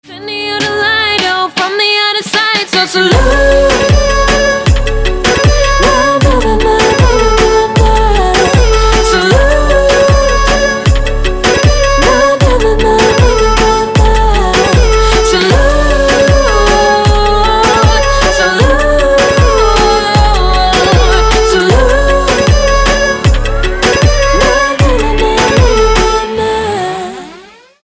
• Качество: 256, Stereo
поп
dance